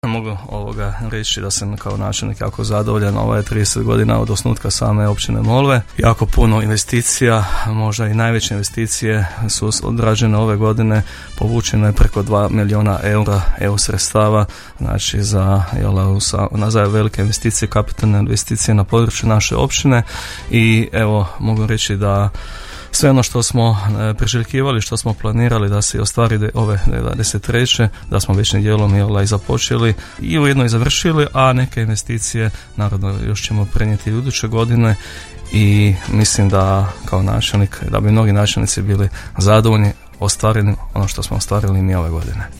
Gost emisije „Susjedne općine” bio je općinski načelnik Općine Molve Zdravko Ivančan koji je godinu na izmakom ocjenio kao vrlo uspješnu: